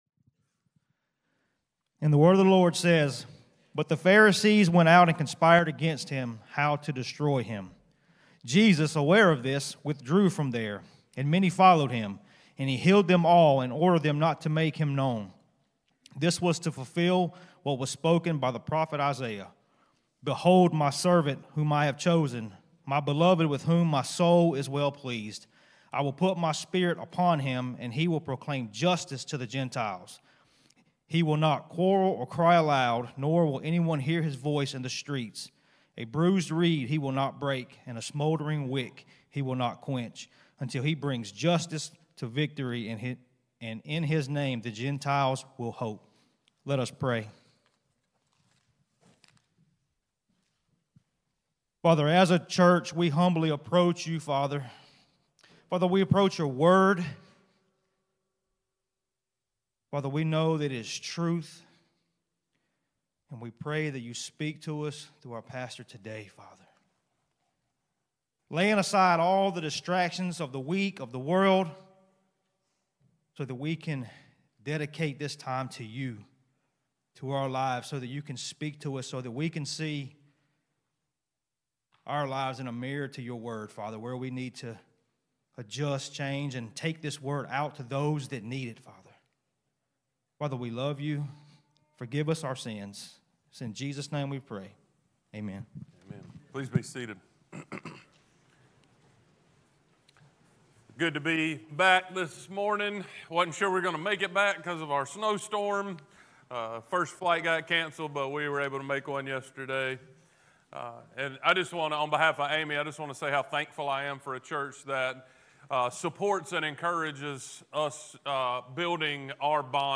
Sermon-1-12-25.mp3